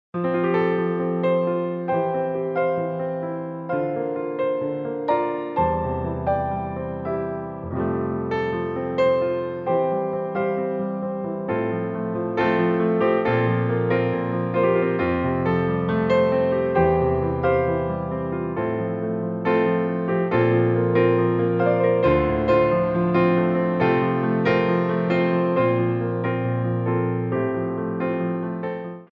Theme Songs from Musicals for Ballet Class
Piano Arrangements
Warm Up
4/4 (8x8)